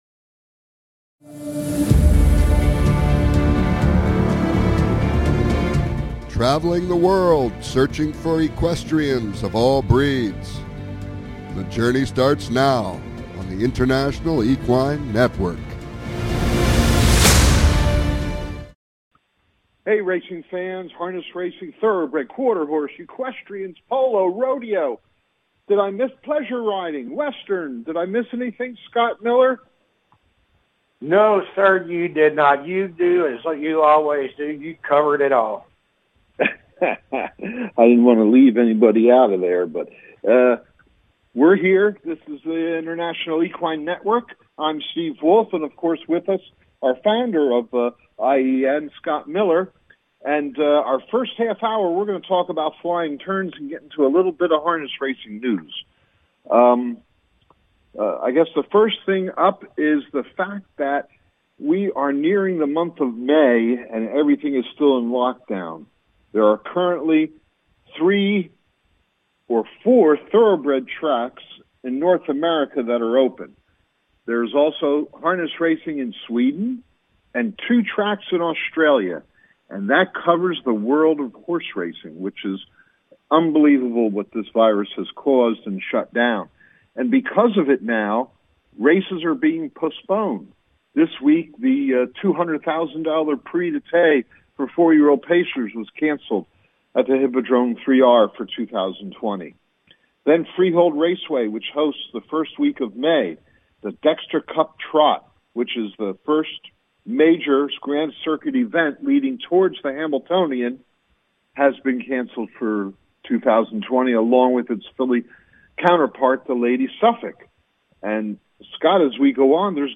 Talk Show Episode
Calls-ins are encouraged!